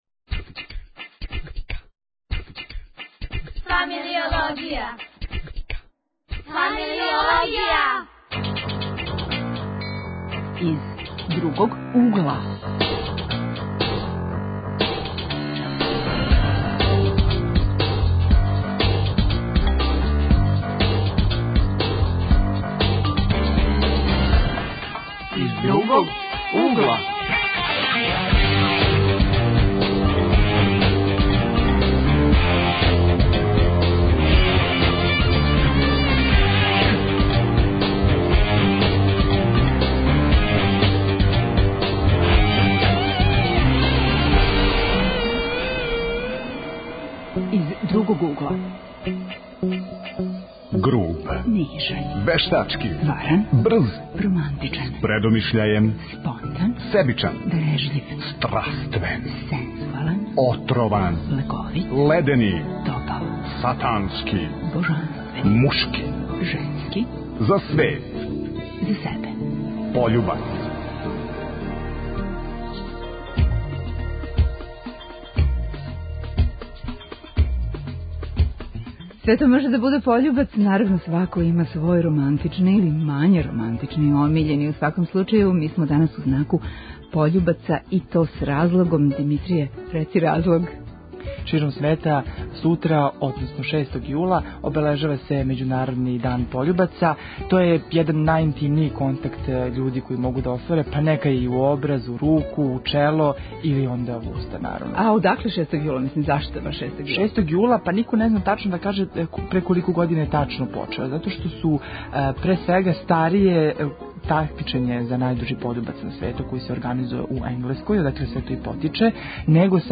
Гости заљубљени тинејџери.